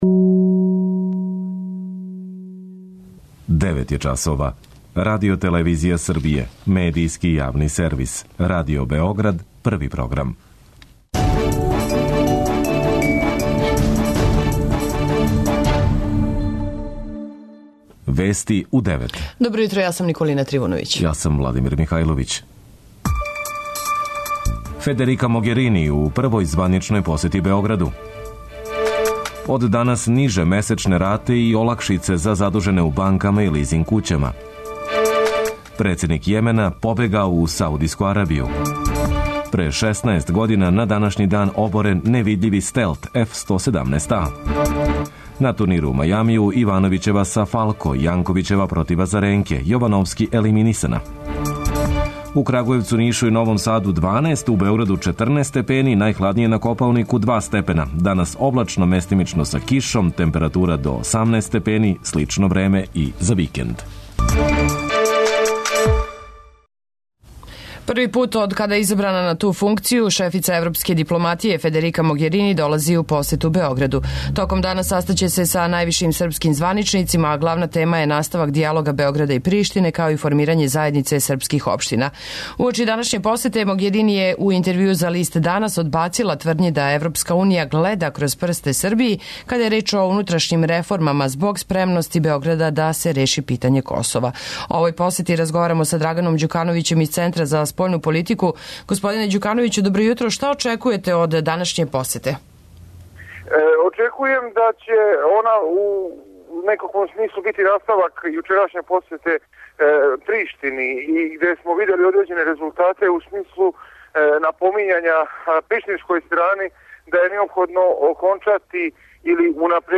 преузми : 10.63 MB Вести у 9 Autor: разни аутори Преглед најважнијиx информација из земље из света.